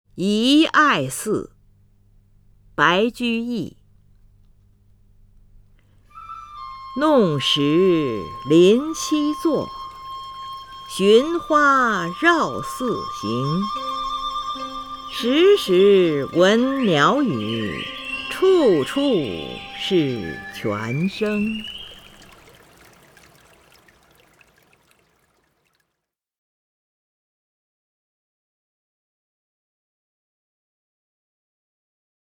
林如朗诵：《遗爱寺》(（唐）白居易) （唐）白居易 名家朗诵欣赏林如 语文PLUS